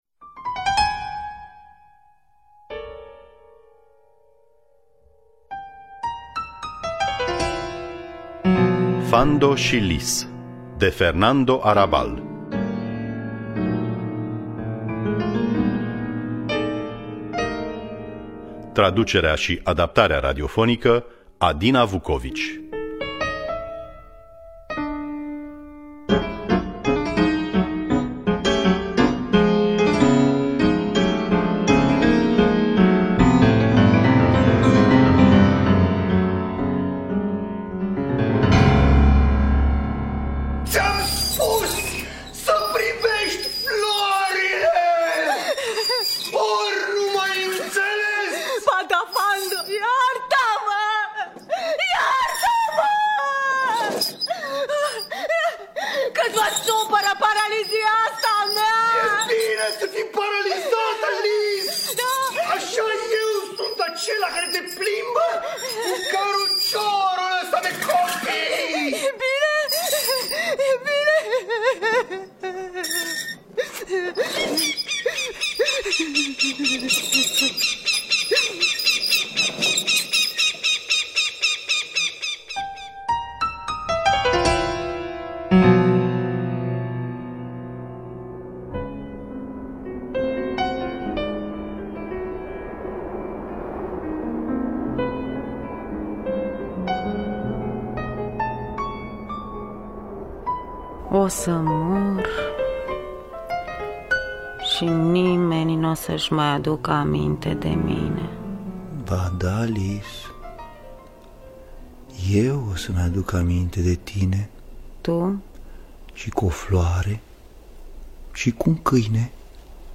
Fando și Lis de Fernando Arrabal Terán – Teatru Radiofonic Online